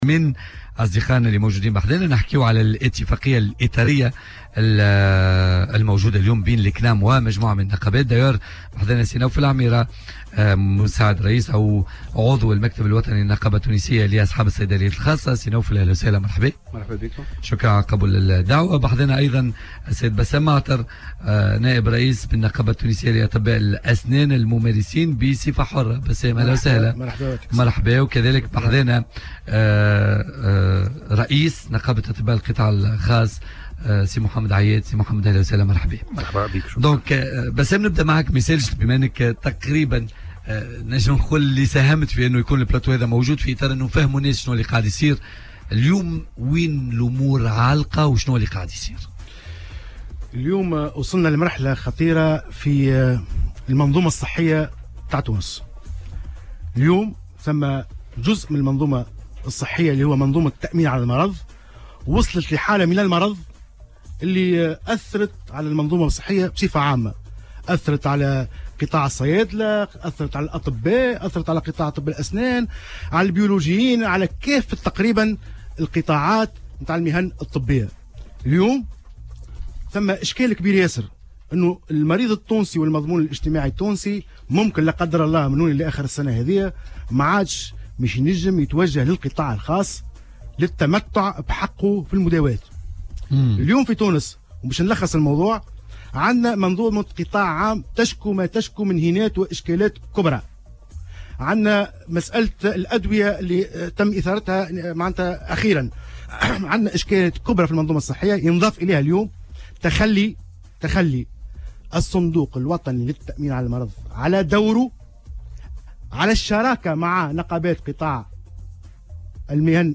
خصص برنامج "بوليتيكا" على "الجوهرة اف أم" اليوم الخميس منبرا حول الصعوبات التي تعاني منها مختلف المهن بقطاع الصحة بسبب أزمة الصناديق الاجتماعية وأساسا الكنام.